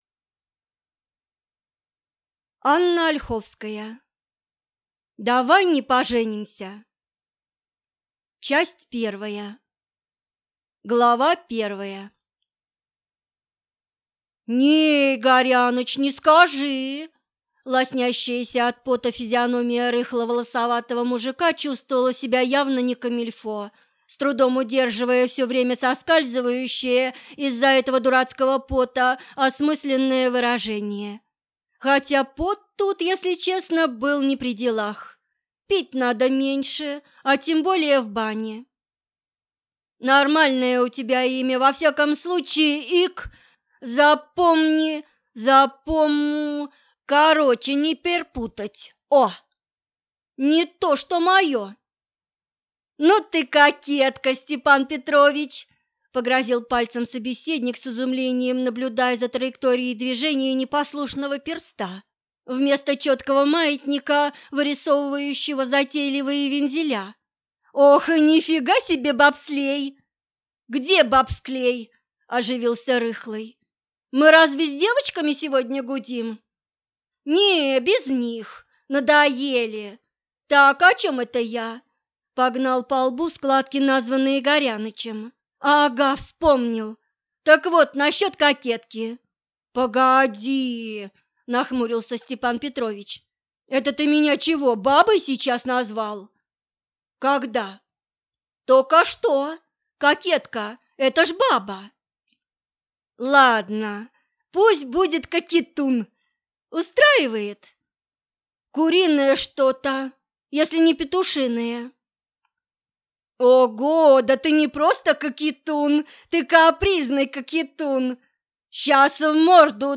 Аудиокнига Давай не поженимся!